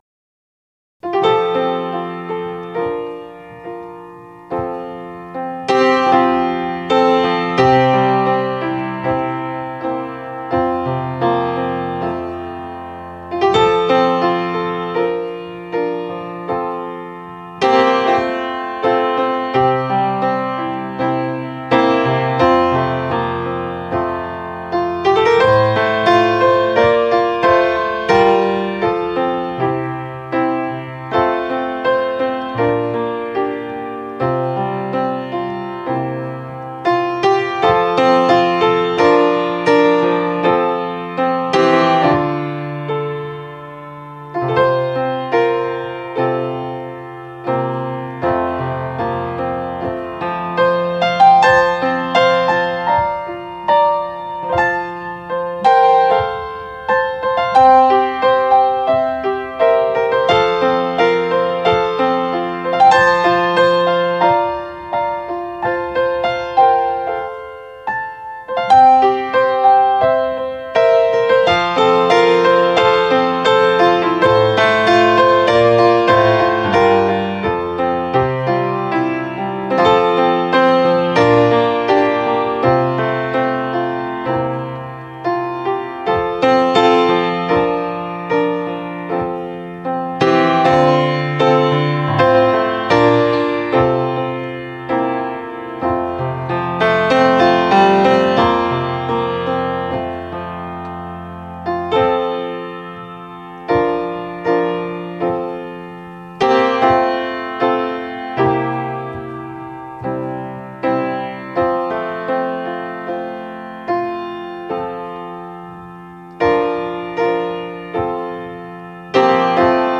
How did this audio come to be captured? I had to minimize the files, so unfortunately the quality is not the best. Also, most of these were recorded on the first take so you may hear mistakes here or there (i.e. copyist errors…).